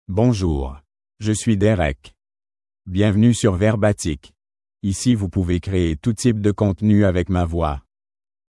MaleFrench (Canada)
Derek is a male AI voice for French (Canada).
Voice sample
Male
Derek delivers clear pronunciation with authentic Canada French intonation, making your content sound professionally produced.